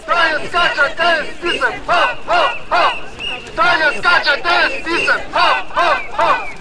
Akcja "Stargard dla demokracji" na Rynku Staromiejskim